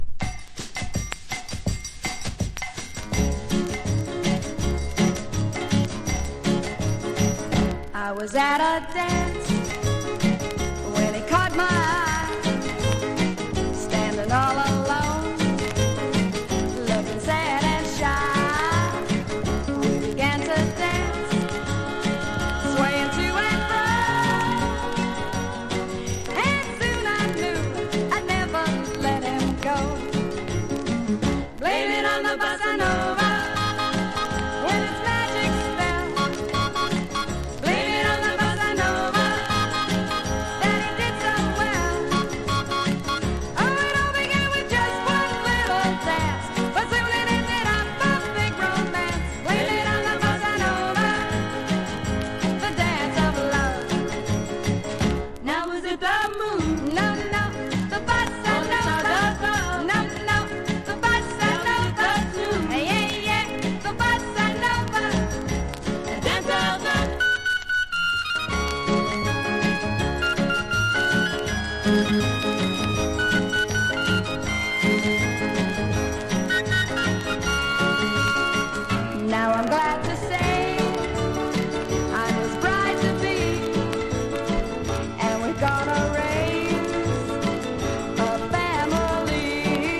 VOCAL & POPS